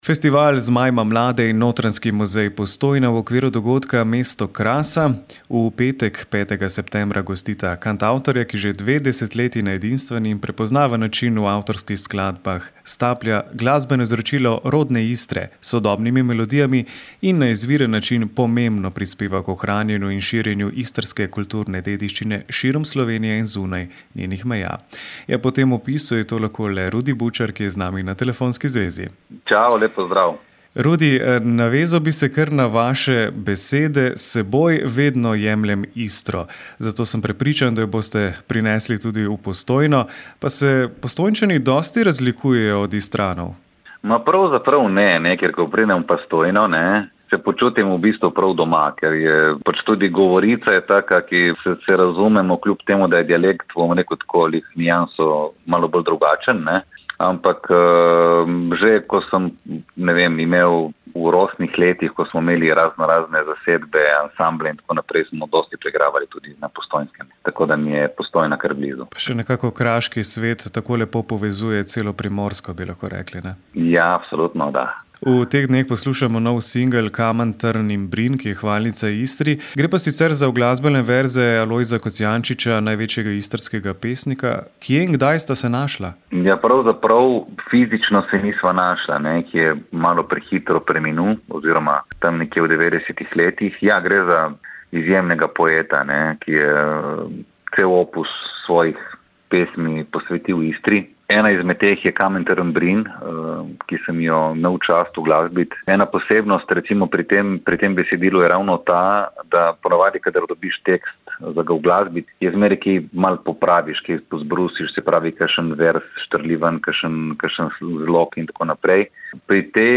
Pred koncertom v Postojni smo se pogovarjali s kantavtorjem, ki že dve desetletji na edinstven in prepoznaven način v avtorskih skladbah staplja glasbeno izročilo rodne Istre s sodobnimi melodijami in na izviren način pomembno prispeva k ohranjanju in širjenju istrske kulturne dediščine širom Slovenije in zunaj njenih meja.